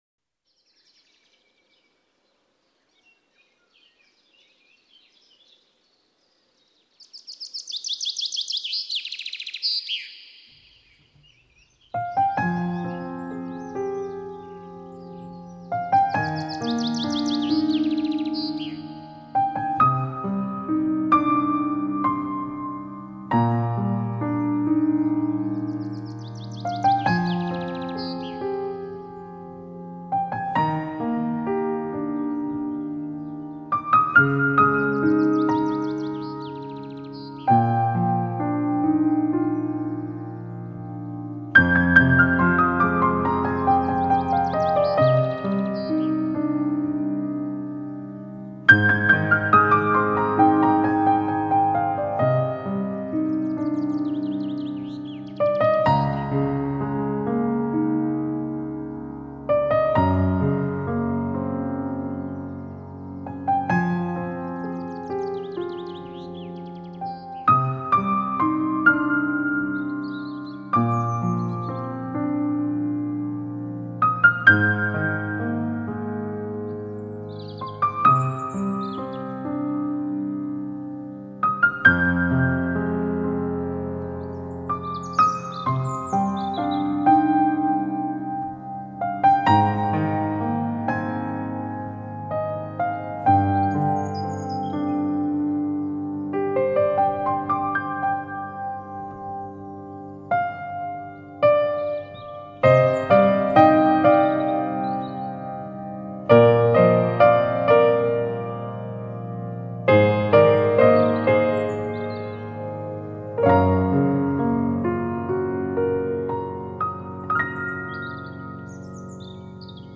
钢琴演奏